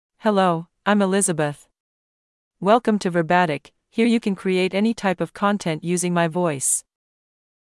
FemaleEnglish (United States)
Elizabeth is a female AI voice for English (United States).
Voice sample
Female
Elizabeth delivers clear pronunciation with authentic United States English intonation, making your content sound professionally produced.